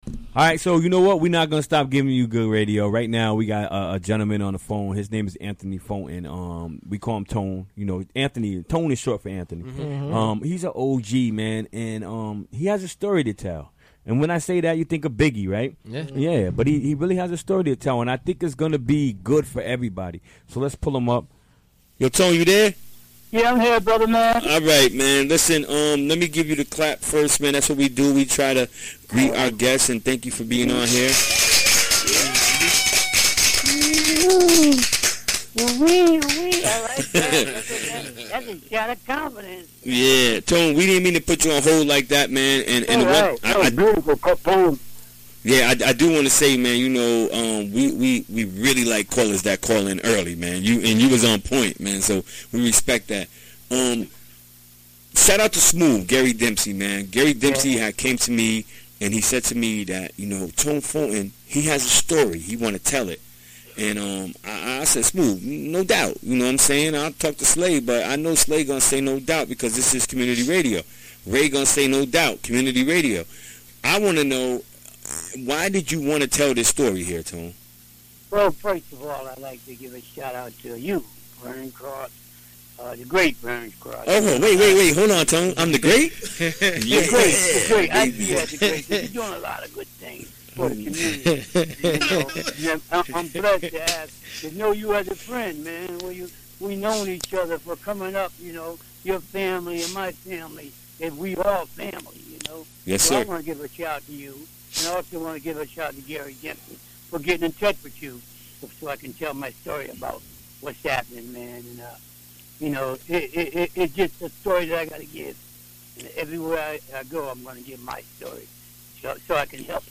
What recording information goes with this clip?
Recorded during the WGXC Afternoon Show Wednesday, December 27, 2017.